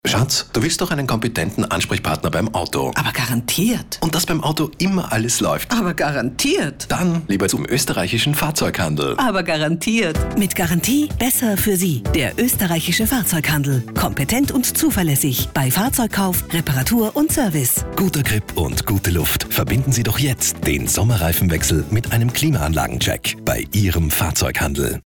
Im Frühjahr 2017 wird der neue Radiospot des Fahrzeughandels österreichweit ausgestrahlt.